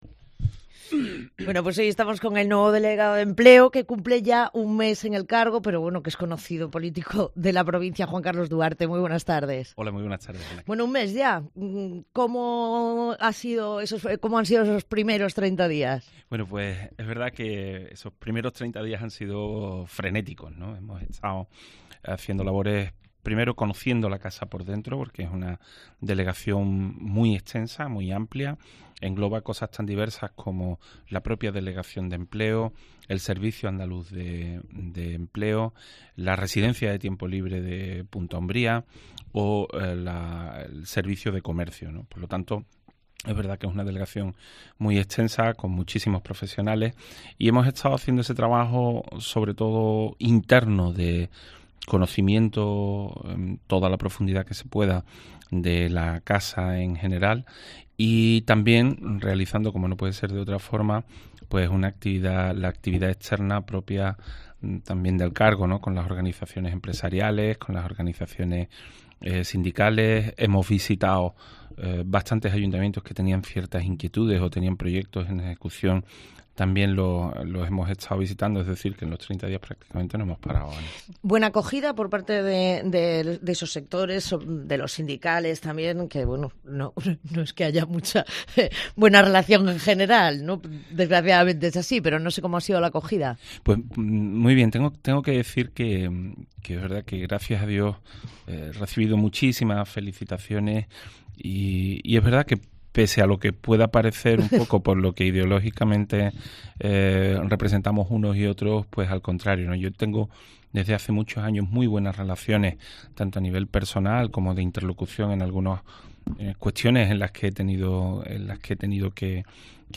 Juan Carlos Duarte, delegado de Empleo